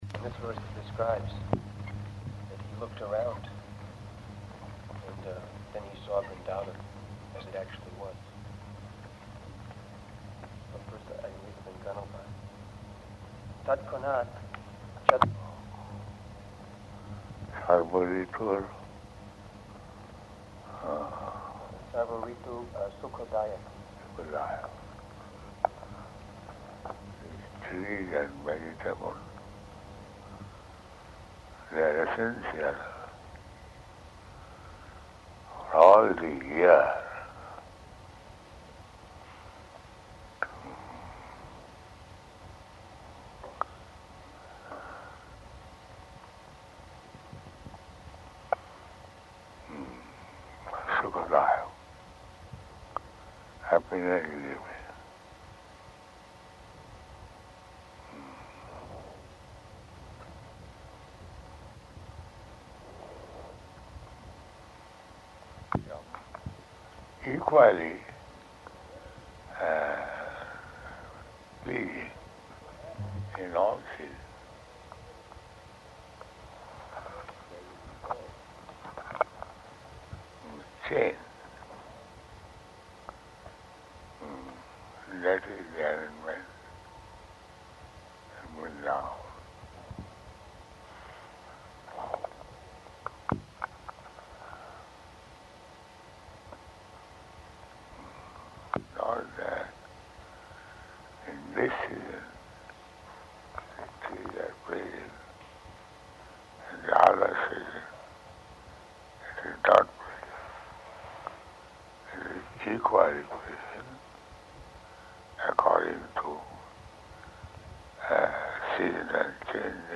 Śrīmad-Bhāgavatam Dictation